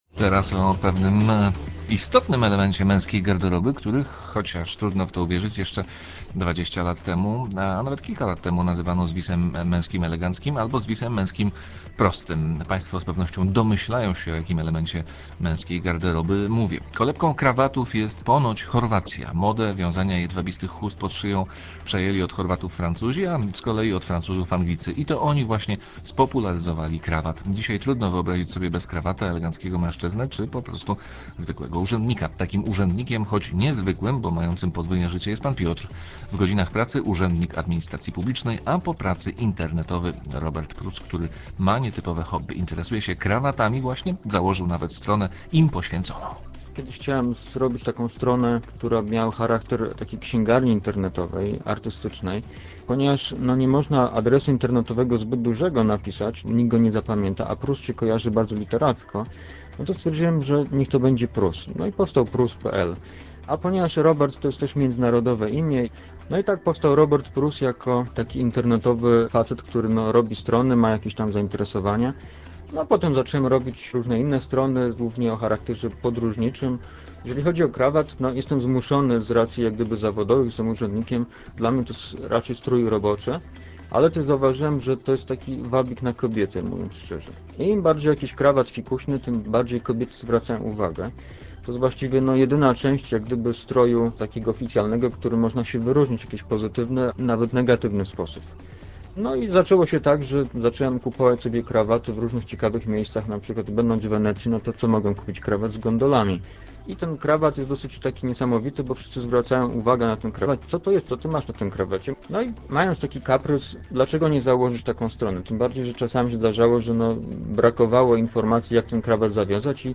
Dźwiękowy zapis Trójkowej audycji można znaleźć poniżej: